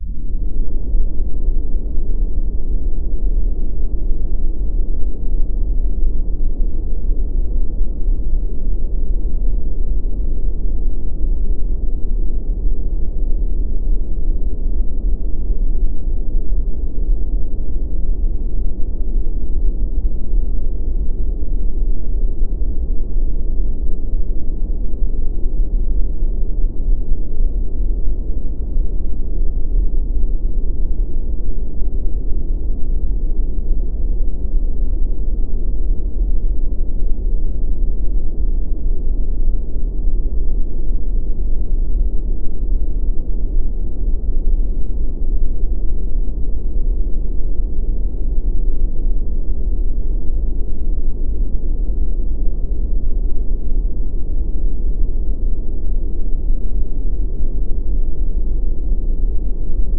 Blood Splashing effects VFX Green sound effects free download